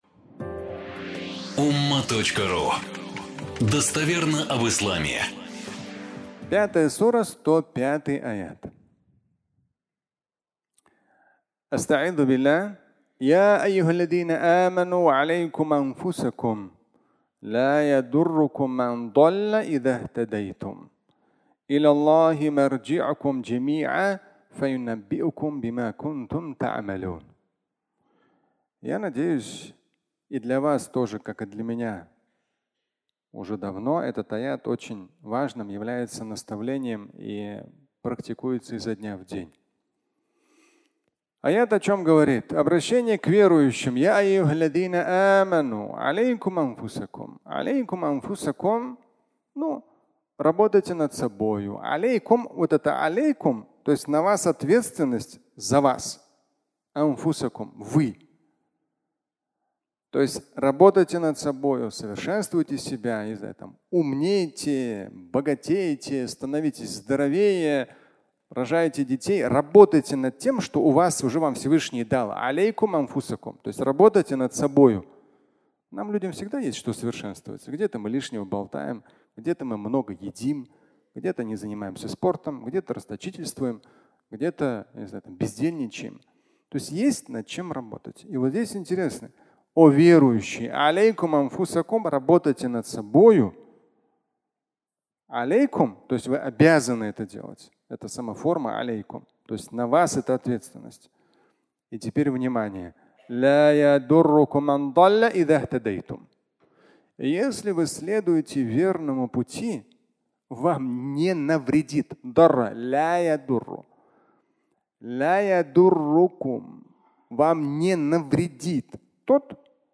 Убеждение верующего (аудиолекция)